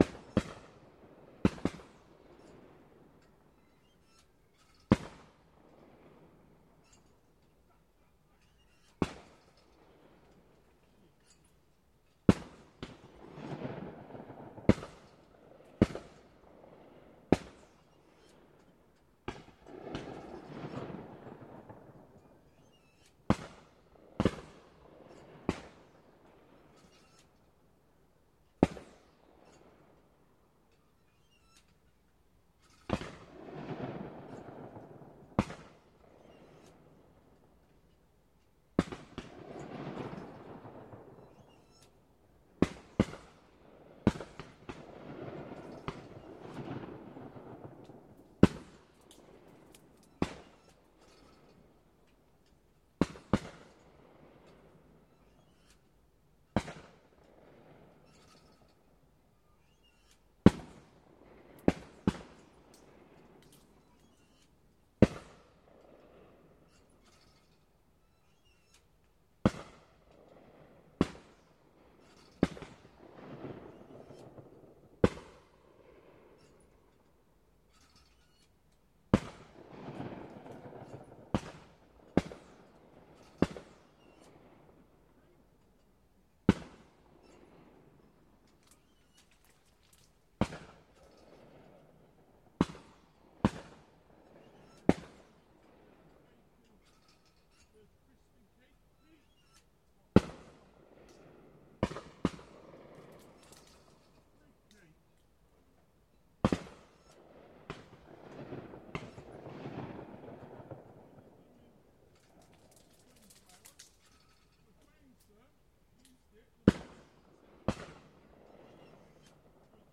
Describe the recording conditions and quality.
A clay pigeon shoot in the countryside outside Bath, England.